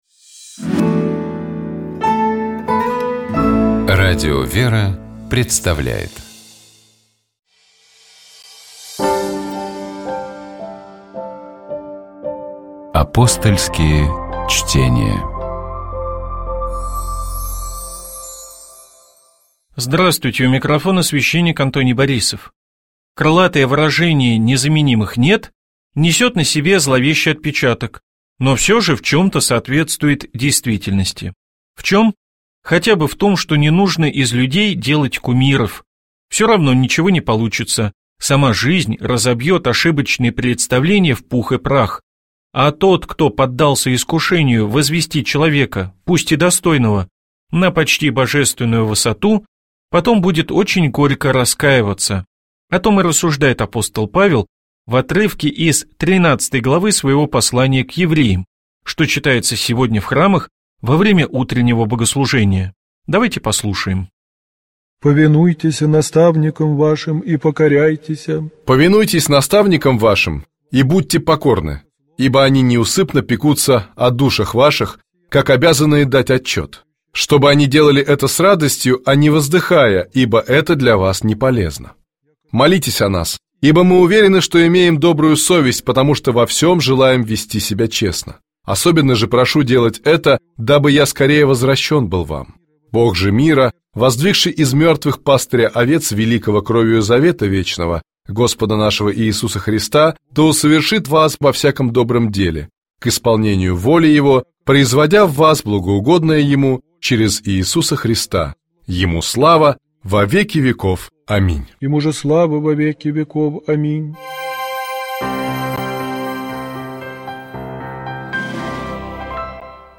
Апостольские чтения